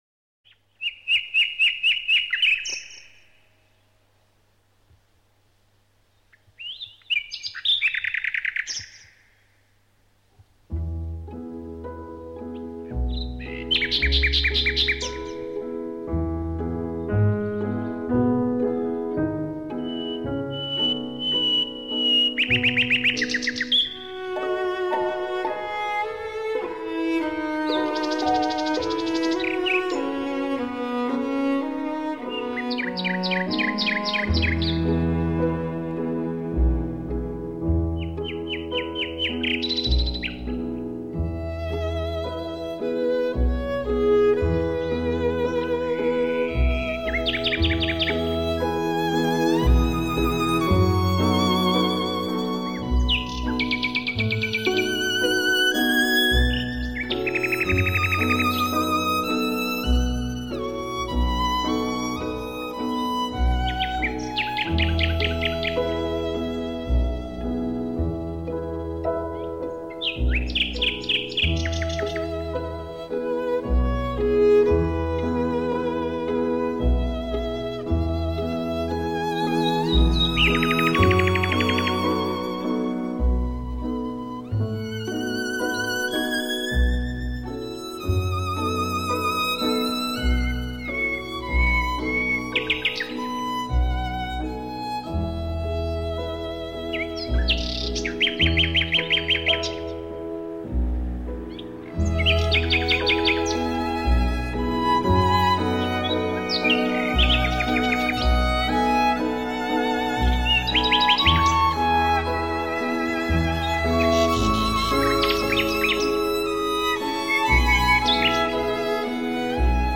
音乐类型：新世纪音乐(NEW AGE)